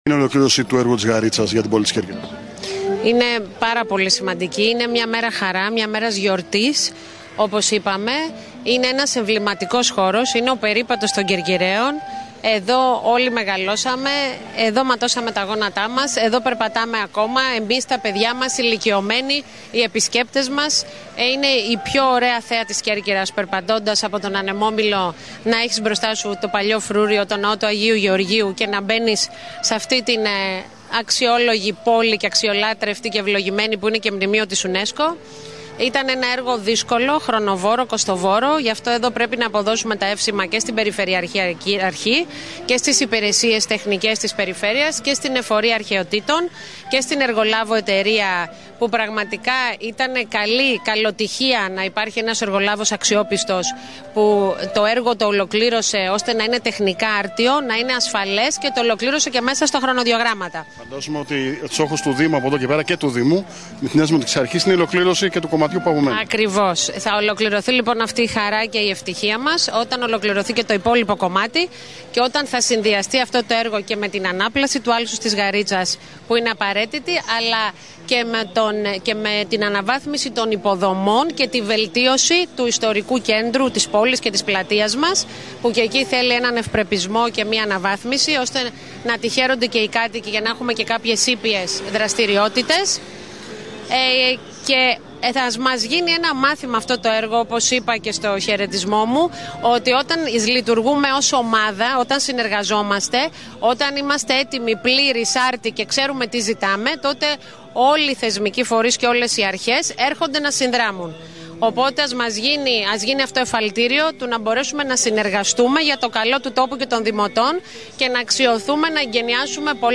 ΜΕΡΟΠΗ ΥΔΡΑΙΟΥ
ΔΗΜΑΡΧΟΣ ΚΕΝΤΡΙΚΗΣ ΚΕΡΚΥΡΑΣ